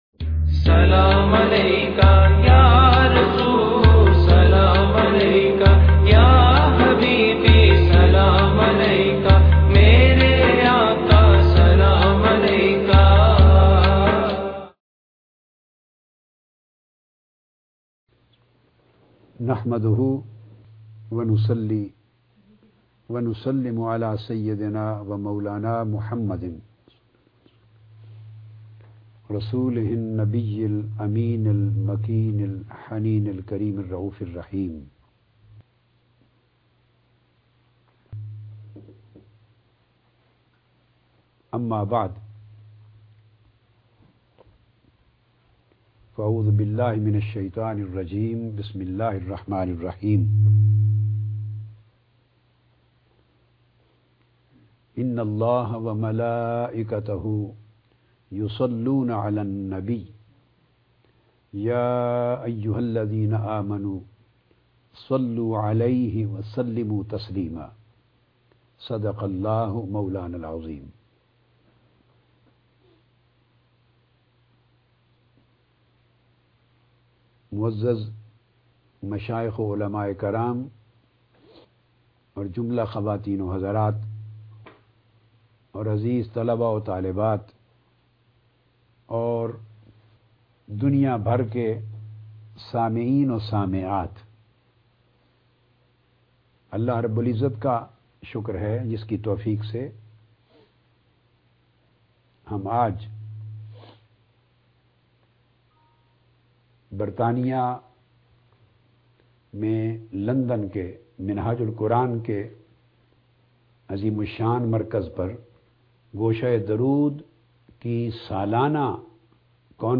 Darood o slam duwa ki qabooliyat ka zamin mp3 speech dr tahir ul qadri download